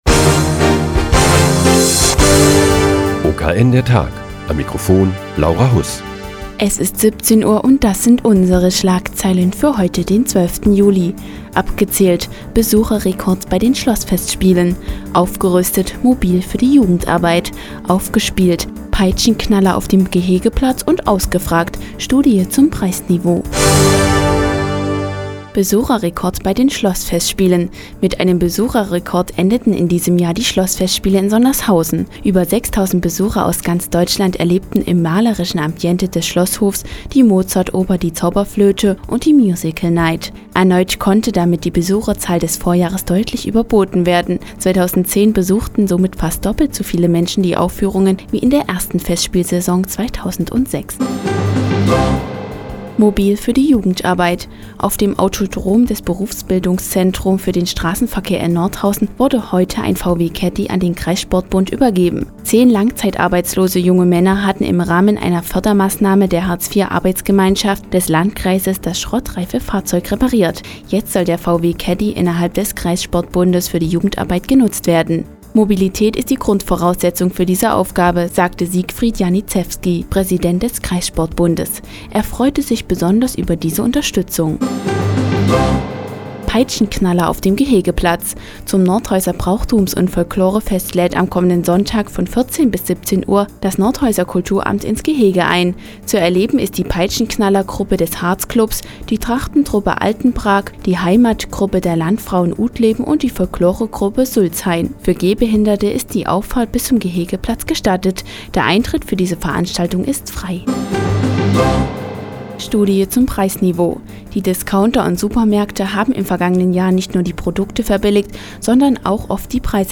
Die tägliche Nachrichtensendung des OKN ist nun auch in der nnz zu hören. Heute geht es um den Erfolg der diesjährigen Schlossfestspiele in Sondershausen und das Nordhäuser Brauchtums- und Folklorefest kommenden Sonntag.